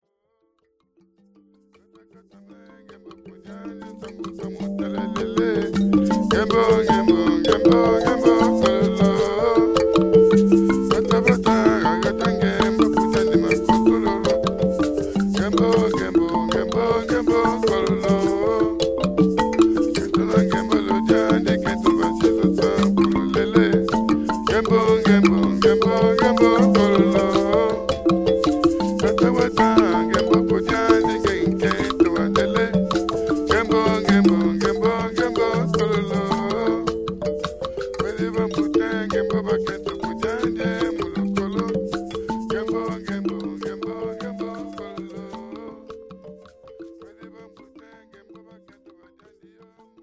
African-inspired